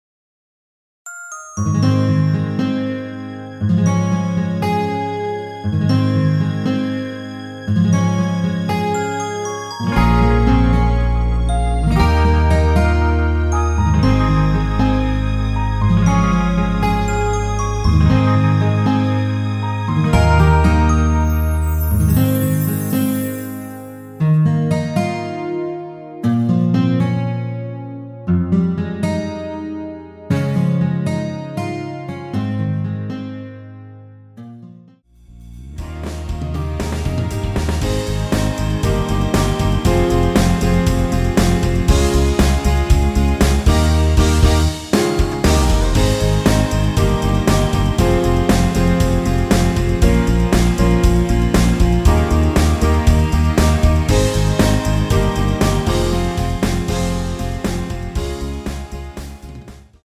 엔딩이 페이드 아웃이라 엔딩을 만들어 놓았습니다.
Ab
앞부분30초, 뒷부분30초씩 편집해서 올려 드리고 있습니다.